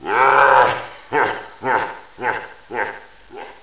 laugh3.wav